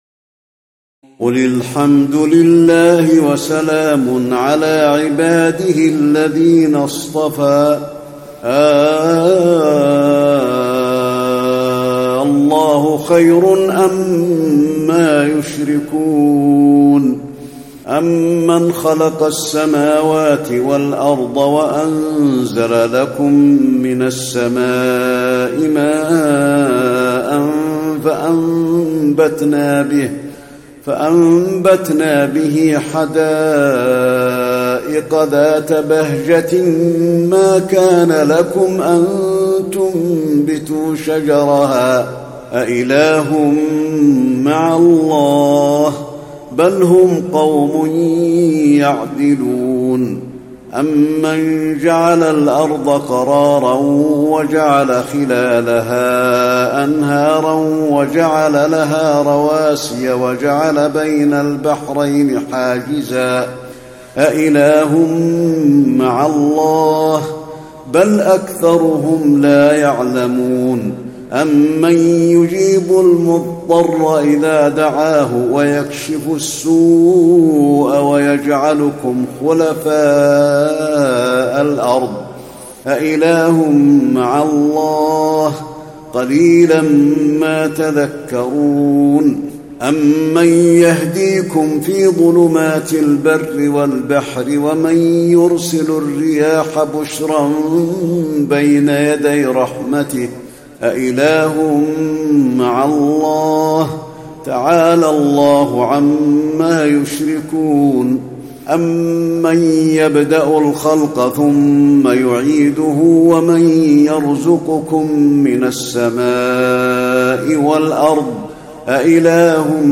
تراويح الليلة التاسعة عشر رمضان 1435هـ من سورتي النمل(59-93) و القصص(1-50) Taraweeh 19 st night Ramadan 1435H from Surah An-Naml and Al-Qasas > تراويح الحرم النبوي عام 1435 🕌 > التراويح - تلاوات الحرمين